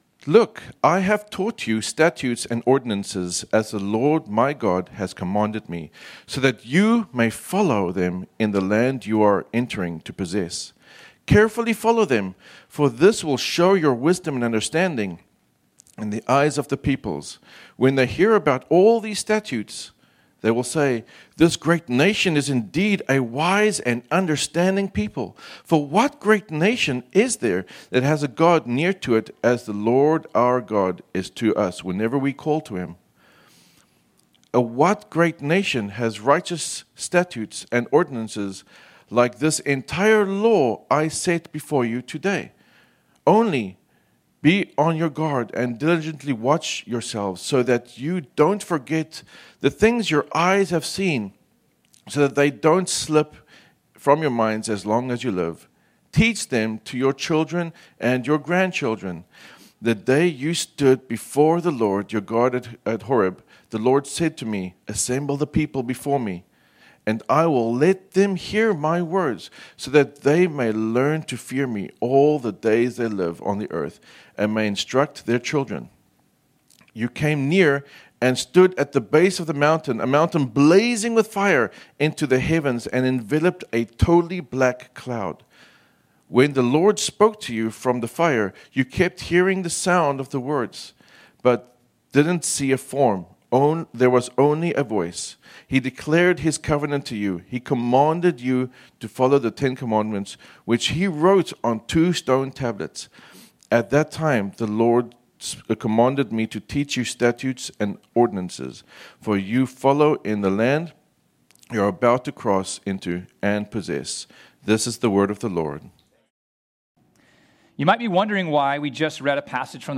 ” our sermon series on the book of Exodus.